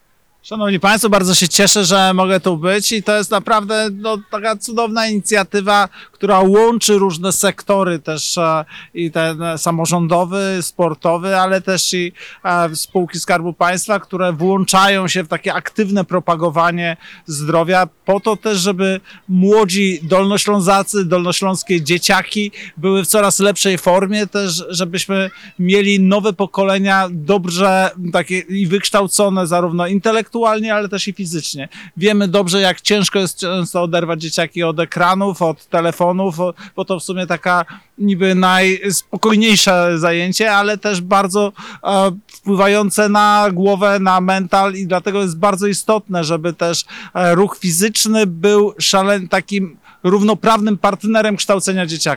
Warto odnotować, że projekt odbywa się pod patronatem Marszałka Województwa Dolnośląskiego Pawła Gancarza oraz sekretarza stanu w Ministerstwie Aktywów Państwowych Roberta Kropiwnickiego, który zwraca uwagę na społeczne znaczenie inicjatywy.